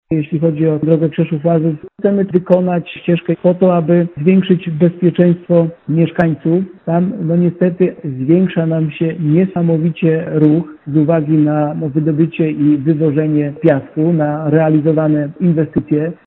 Starosta niżański Robert Bednarz przyznał, że to ważna inwestycja ze względu na konieczność poprawy bezpieczeństwa na tej drodze powiatowej: